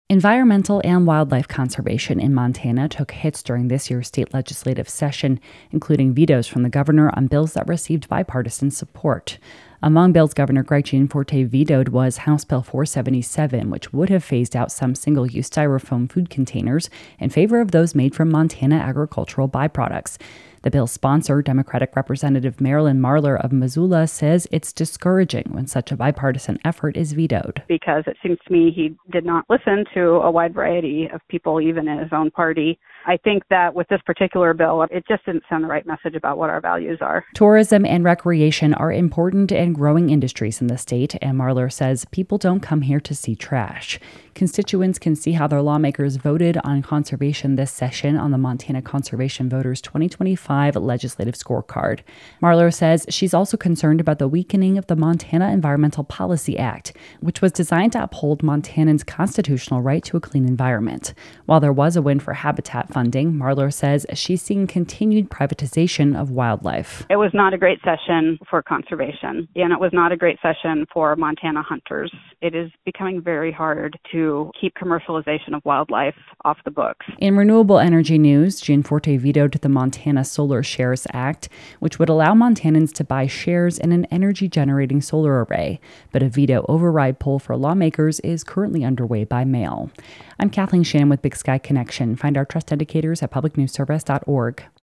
Comments from state Rep. Marilyn Marler, D-Missoula.